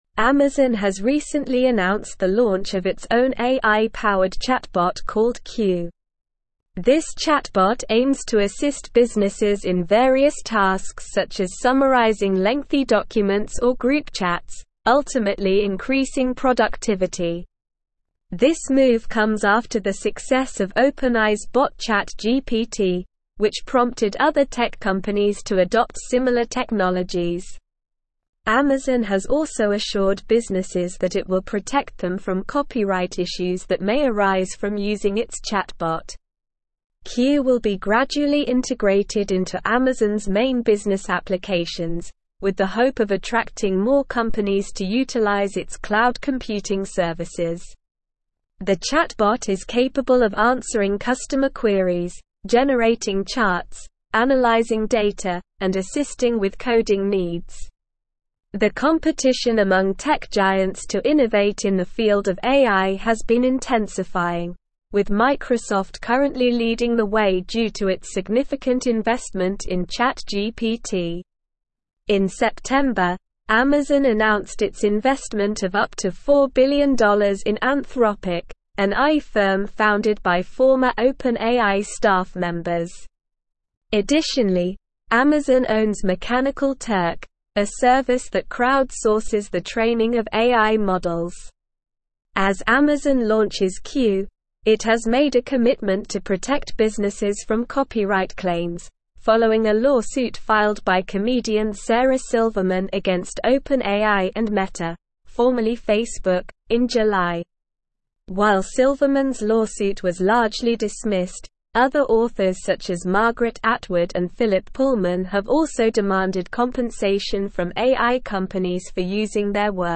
Slow
English-Newsroom-Advanced-SLOW-Reading-Amazon-Introduces-Q-Chatbot-to-Boost-Business-Productivity.mp3